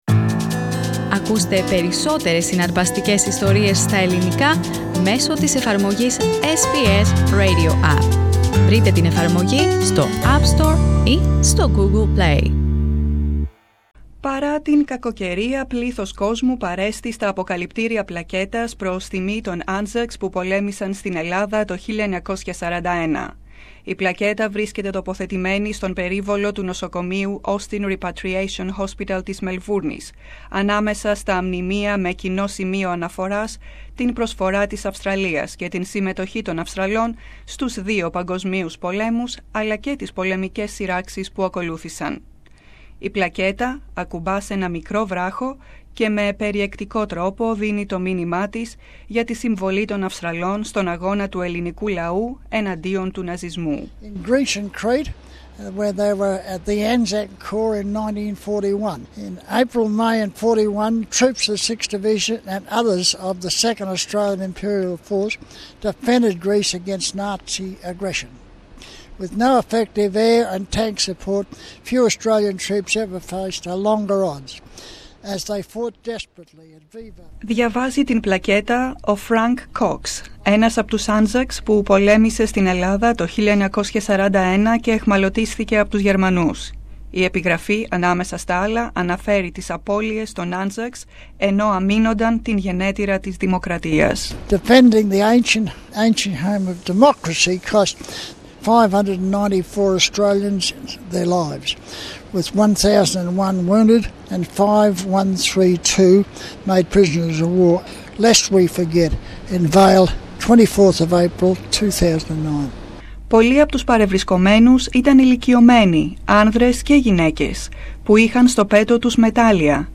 Many decades later, he spoke to SBS Greek about his time in Greece with the 1 Aus Corps at the unveiling of a commemorative plaque at Heidelberg Repatriation Hospital.